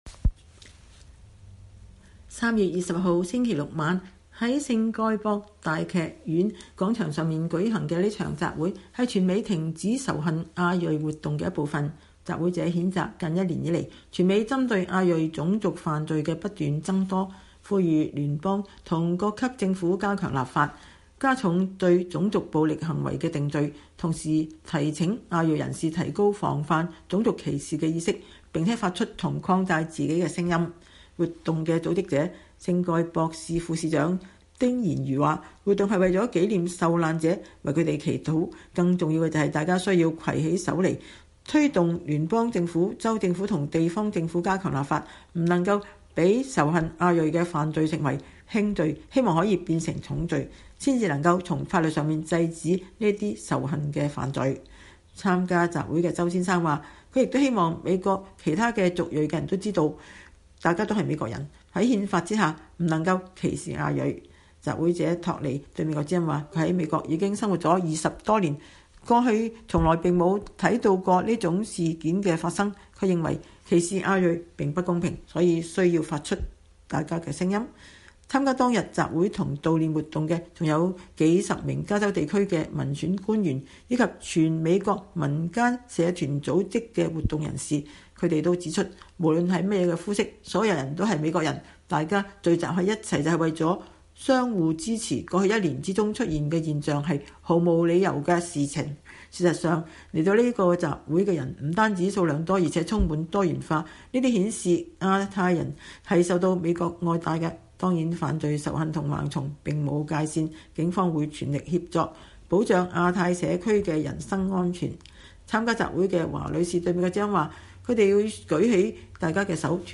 數百人在洛杉磯華人聚集的聖蓋博市舉行集會，悼念亞特蘭大槍擊事件中的不幸喪生者，並呼籲各方提升對仇恨亞裔犯罪的意識。